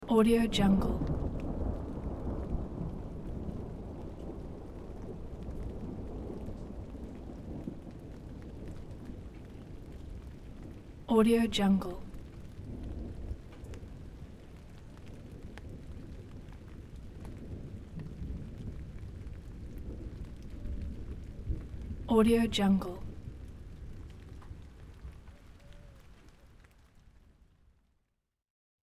دانلود افکت صوتی رعد و برق طولانی و باران ملایم